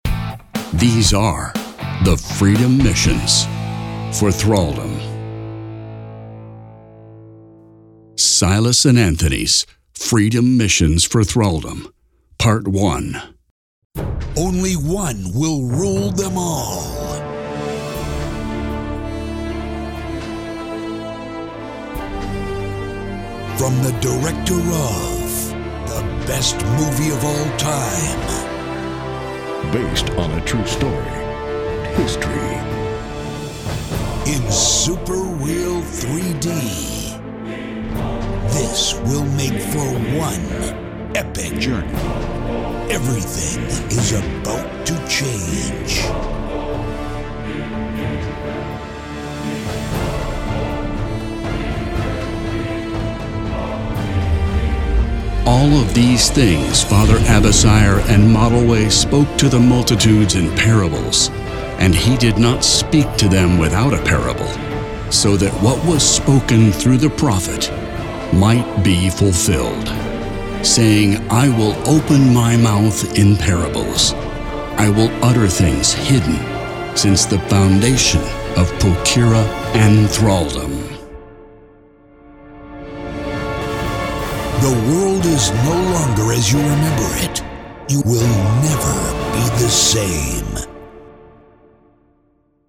Audio TRAILER: Freedom Missions for Thraldom